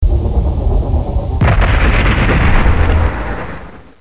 SFX战争中枪声炮声交错音效下载
SFX音效